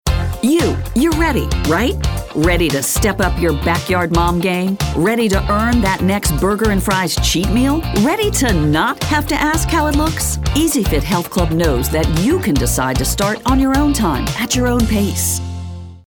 confident, cool, friendly, mature, middle-age, motivational, smooth, thoughtful, warm